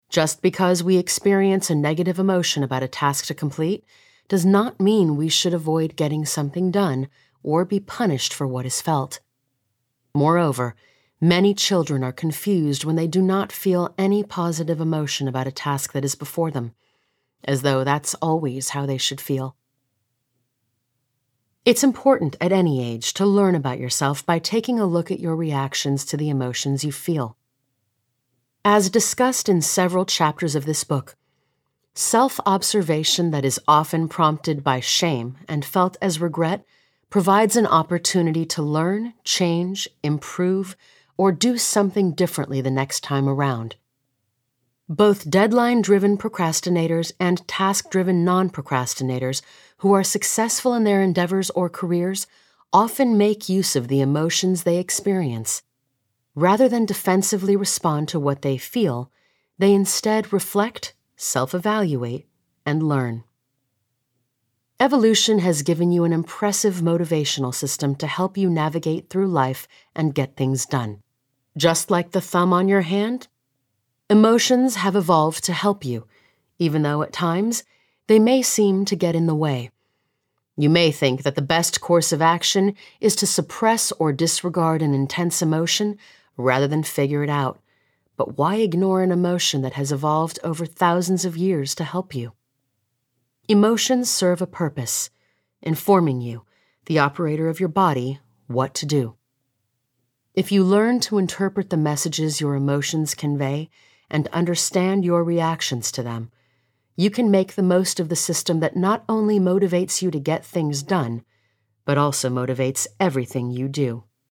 Audiobooks
Her voice range spans between teens, 20s – 30s and middle age, and her accents most known for are American English, British English, Australian/ South African English, American South, French, Italian, New York, South American, Spanish.